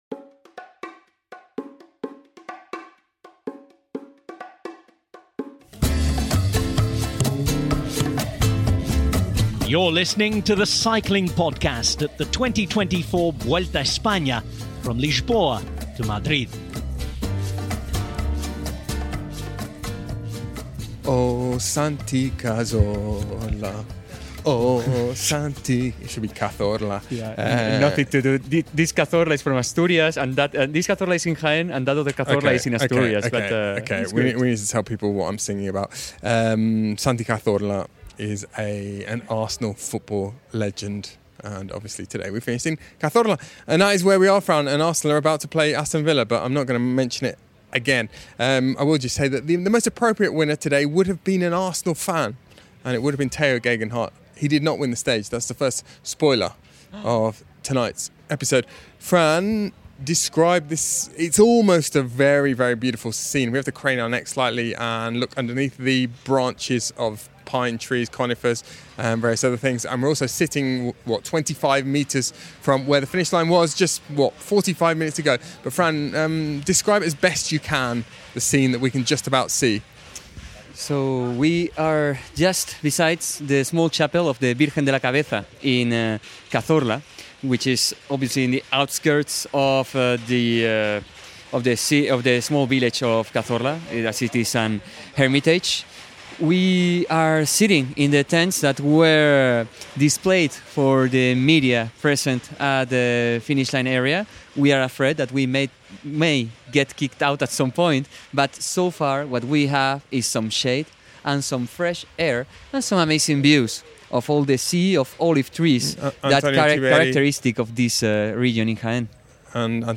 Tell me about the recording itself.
There’ll be analysis, interviews, wistful gazing and tepid takes from on the ground, in the thick of the action - and a return for both popular and unpopular features from previous editions!